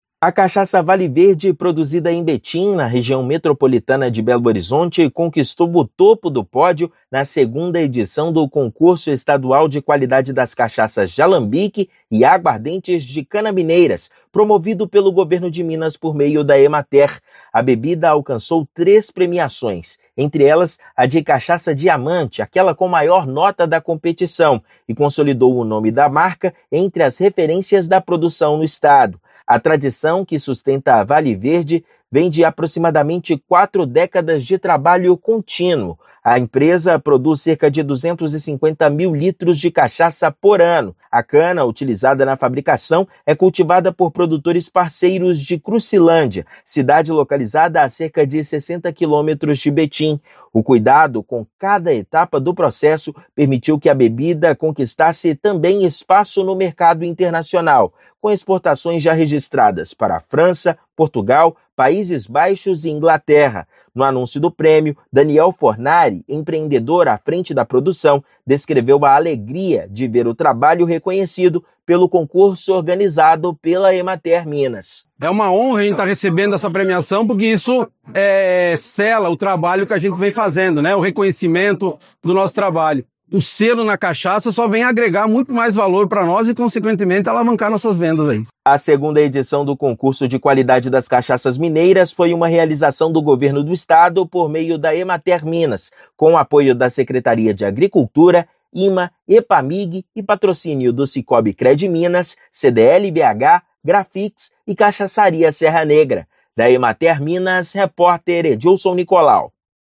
O produto conquistou o topo do pódio na segunda edição do Concurso Estadual das Cachaças de Alambique e Água Ardente. Ouça matéria de rádio.